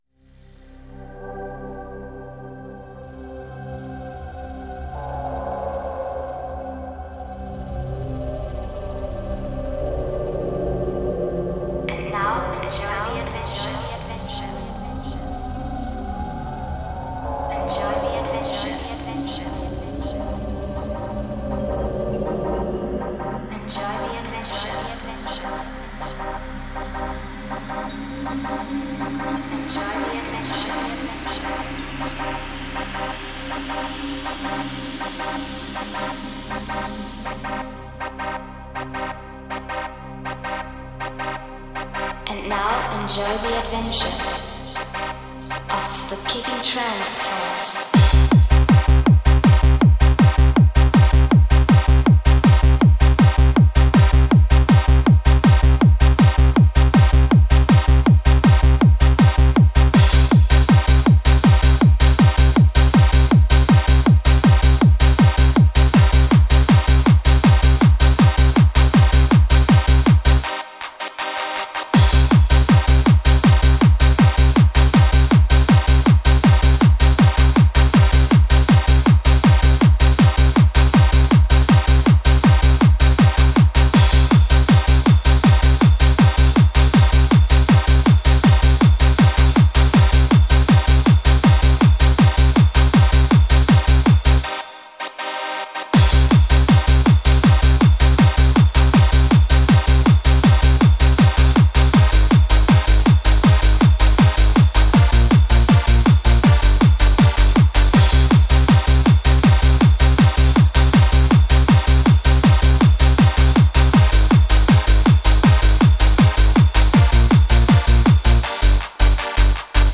Жанр: Trance
Транс, Дискотека 90 - 2000-ые